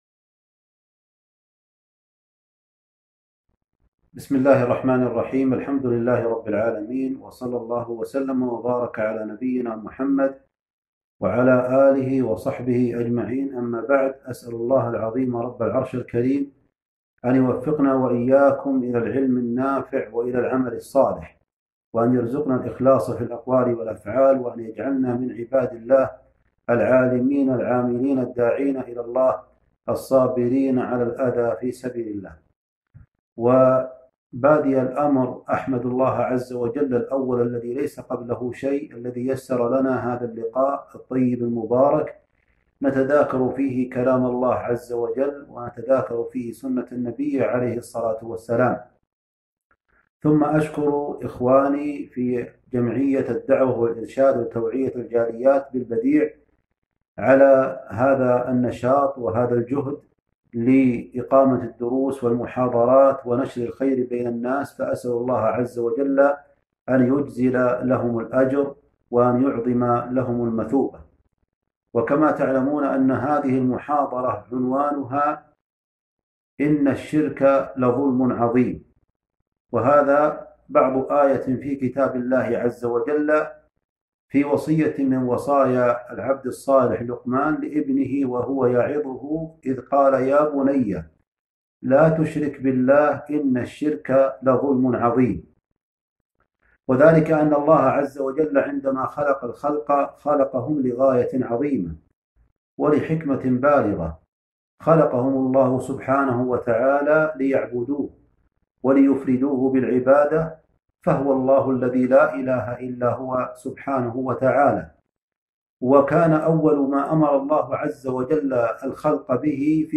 محاضرة بعنوان تفسير قوله تعالى - إن الشرك لظُلم عظيم